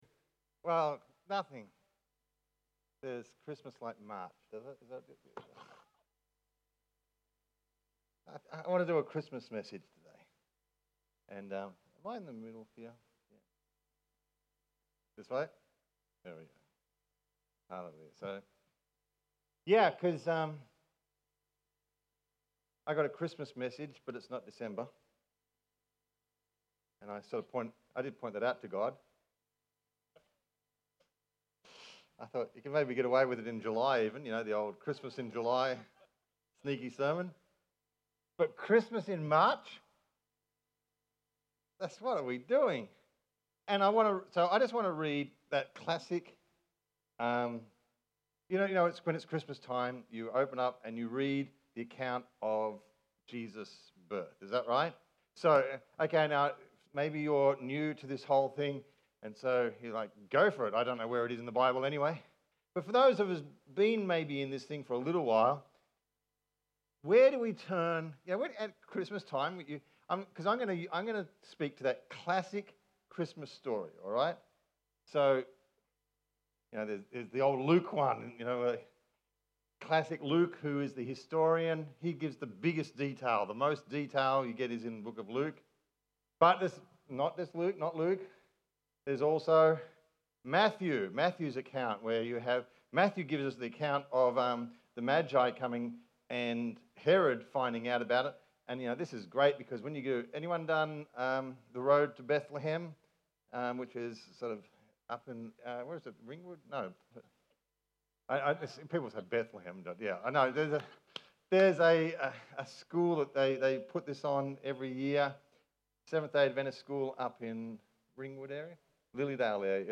A Christmas Sermon...In MARCH?